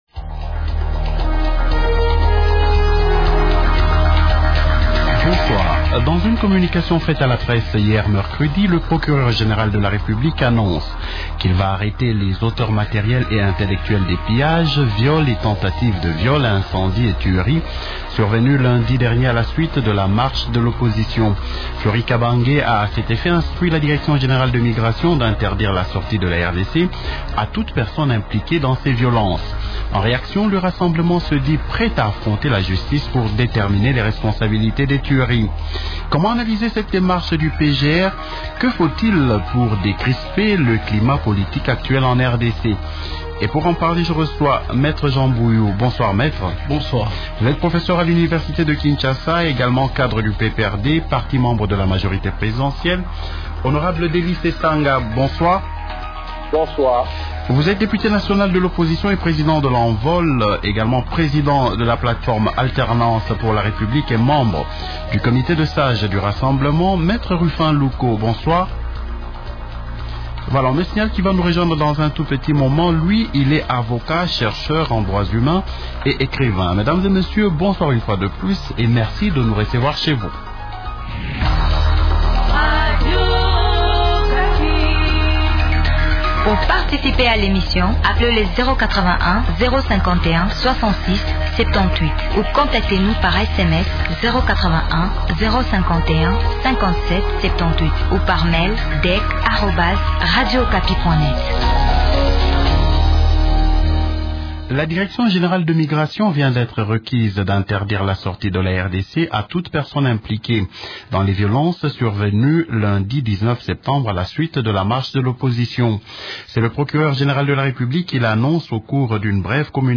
-Delly Sesanga, Député national de l’opposition et président de l’Envol.